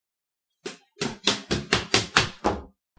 描述：爬楼梯，在家里，赶路Rec Zoom H4
Tag: 渐快 房子 运动 precipitando 加速 步骤